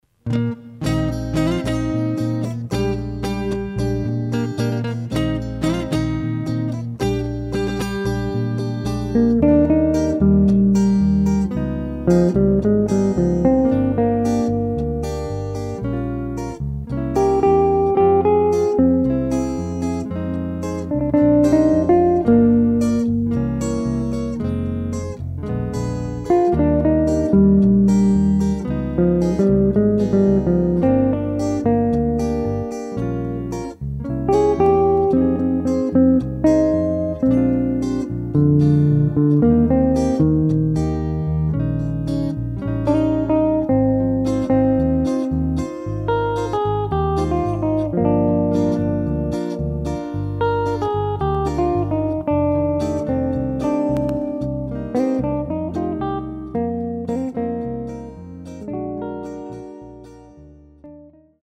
Beguine.